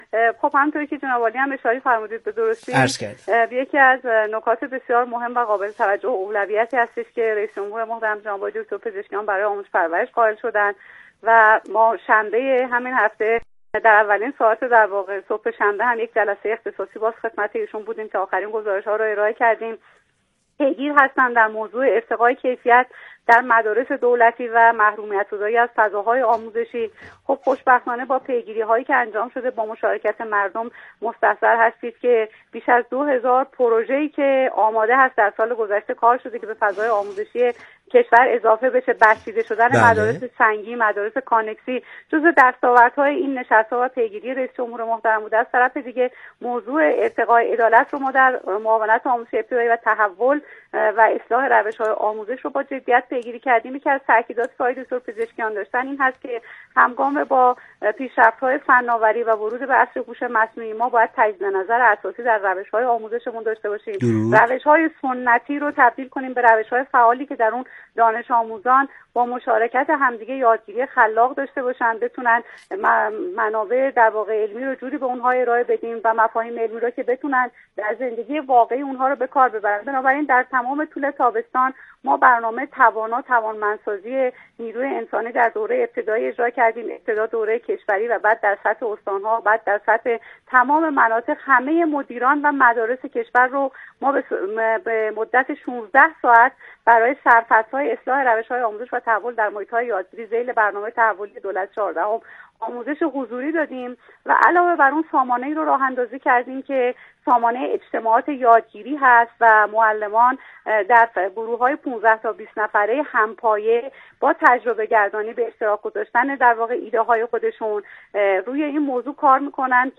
ایکنا به پاس همین اهمیت والا و با درک ژرف از این مسئولیت خطیر، به گفت‌وگو با رضوان حکیم‌زاده، معاون آموزش ابتدایی وزارت آموزش و پرورش نشسته است تا از روایت‌های او در زمینه تحول آموزشی، عدالت محوری و همگامی با نظام آموزش و پروش با فناوری‌های روز در مسیر برداشتن گامی هر چند کوچک در راه اعتلای آموزش و پرورش میهن عزیزمان بگوید و بشنود.